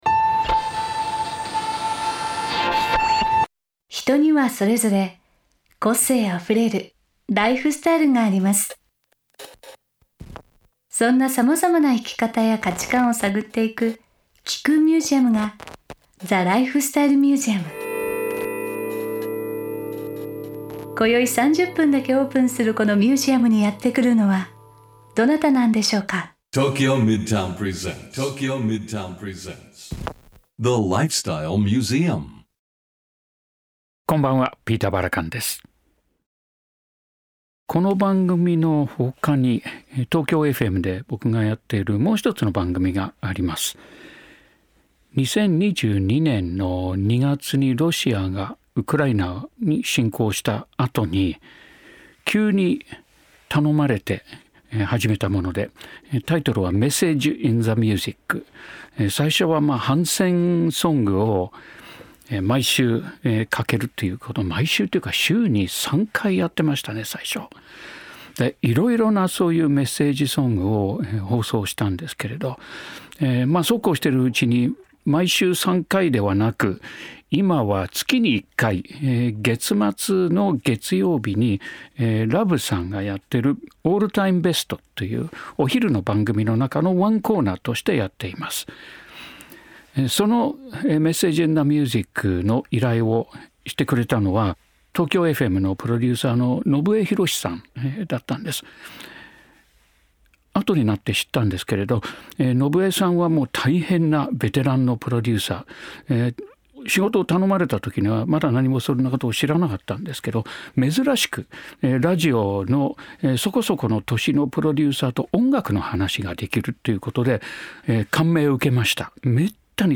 8月8日OAのゲストは、ジャズ・サックス奏者の坂田明さんです。